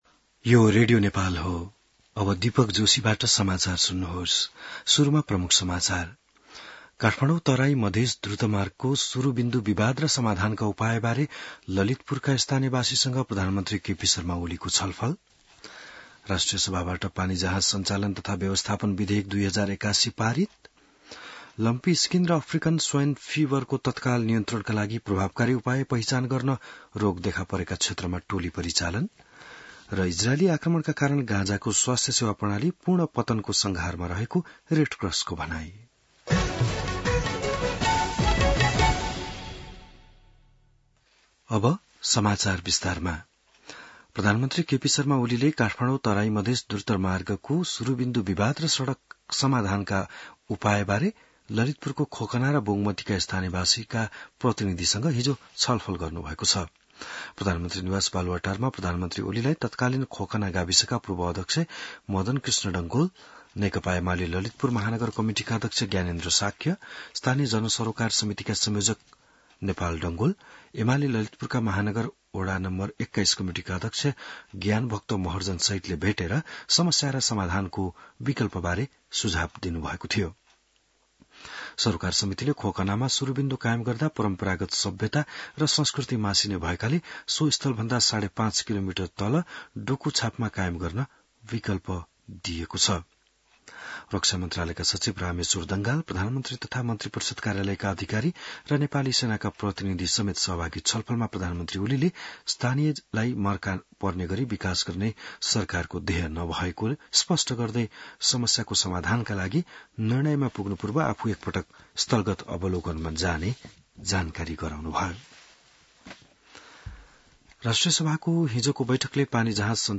An online outlet of Nepal's national radio broadcaster
बिहान ९ बजेको नेपाली समाचार : २७ जेठ , २०८२